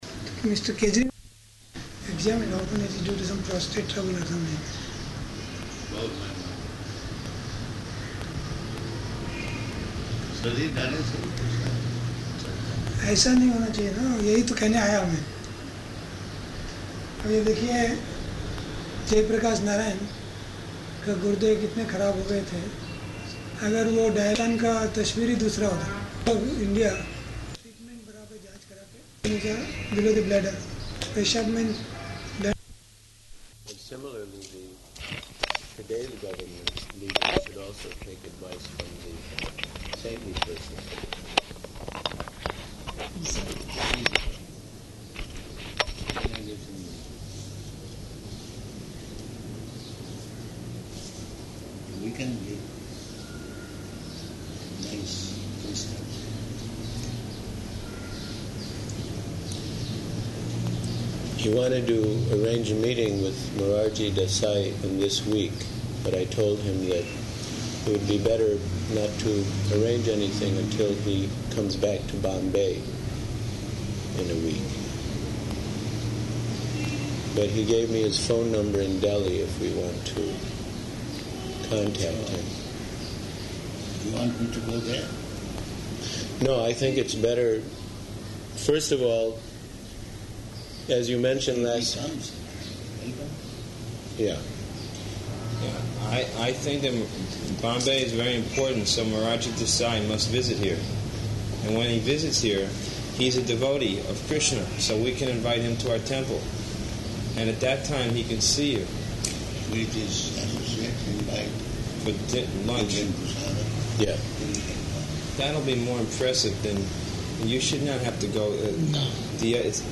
Room Conversation
Type: Conversation
Location: Bombay